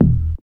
09SYN.BASS.wav